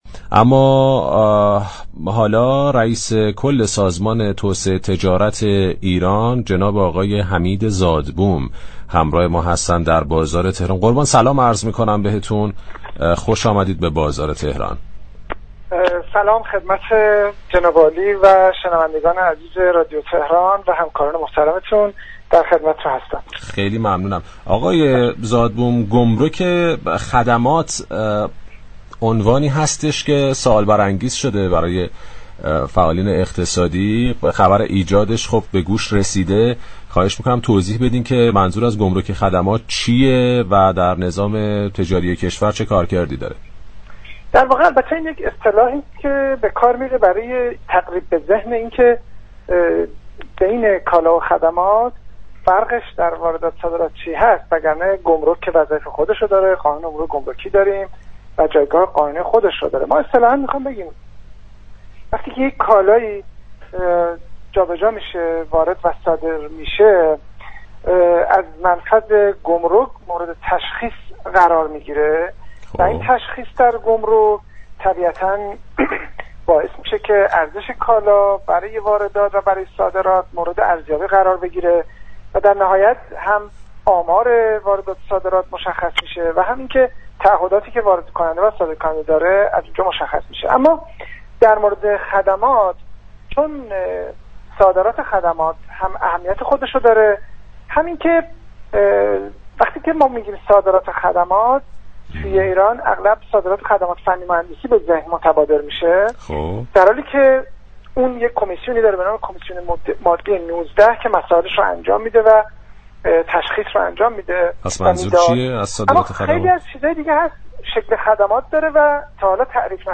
حمید زادبوم در گفتگو با برنامه بازار تهران با اشاره به واژه "گمرك خدمات" ‌و استفاده این اصطلاح برای تفكیك كالا و خدمات گفت: بین كالا و خدمات در فرآیند واردات و صادرات تفاوت‌هایی وجود دارد و گمرك هم وظایف و جایگاه قانونی خود را دارد.